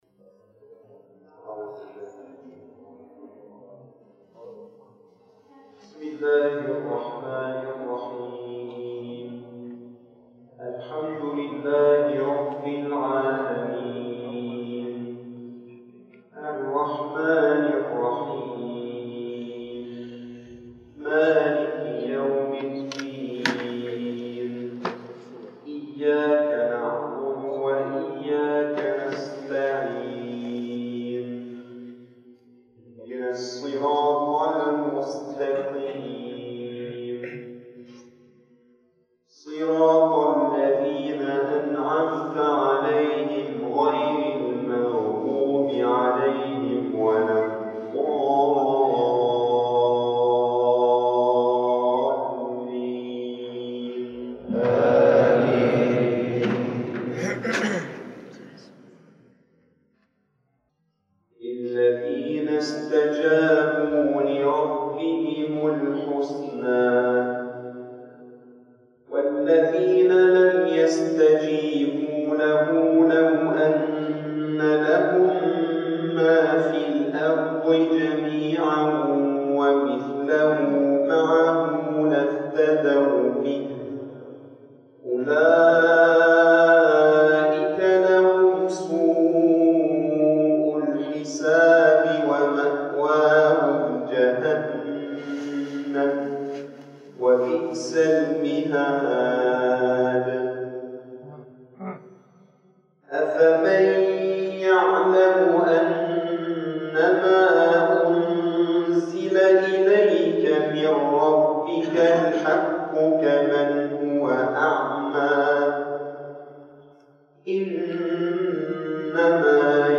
في صلاة فجر اليوم في جامع القلمون الكبير (البحري).
تلاوات